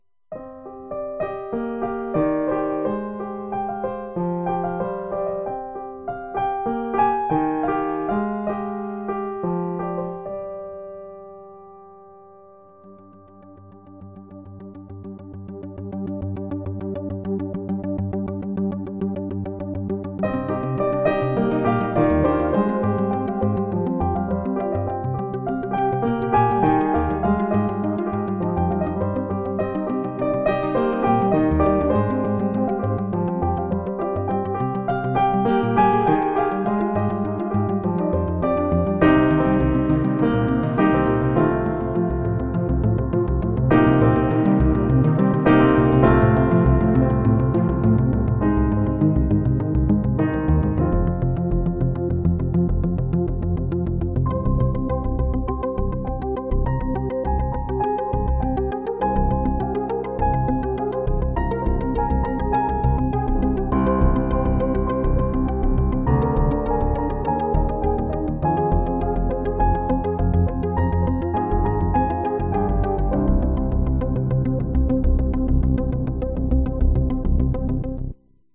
Ο ήχος του πιάνου συνοδεία με συμπαθητικό εικονικό-αναλογικό synthesizer.